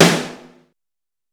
SFX